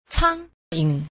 10. 蒼蠅 – cāngyíng – thương dăng (ruồi nhặng)